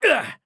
damage_2.wav